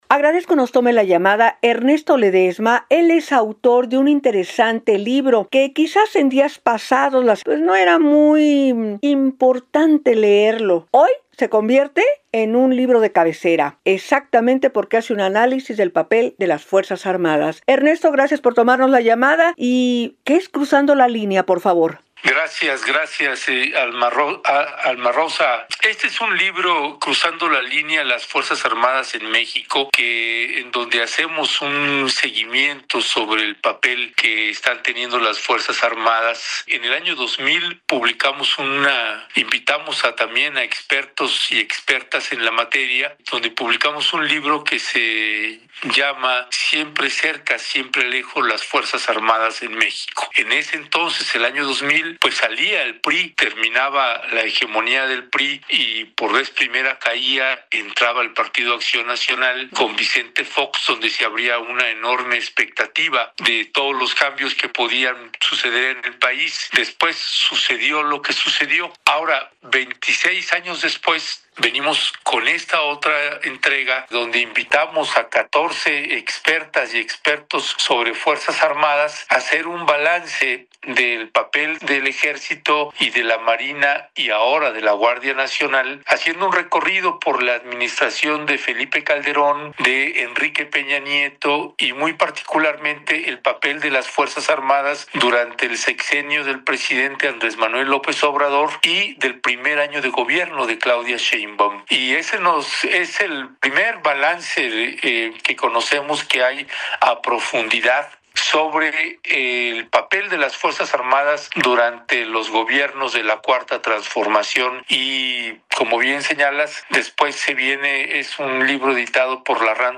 ENTREVISTA-LIBRO-FA.mp3